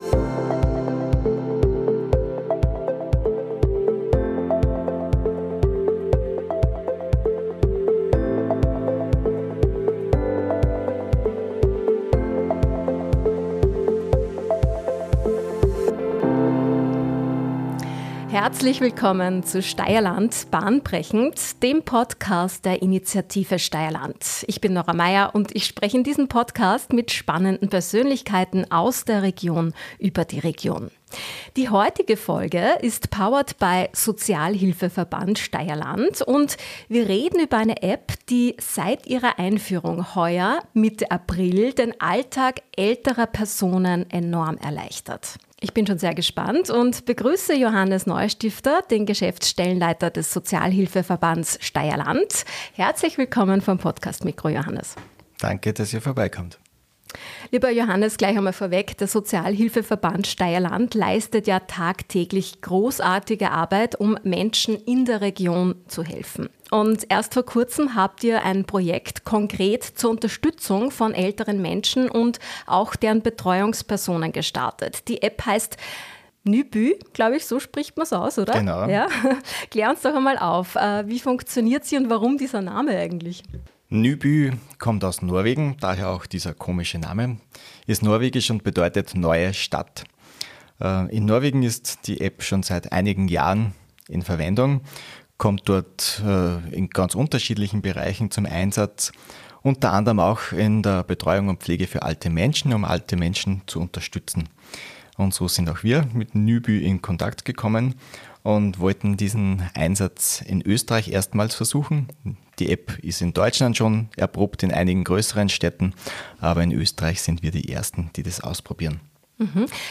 Folge 23: NYBY - Modernes Ehrenamt per App? Im Gespräch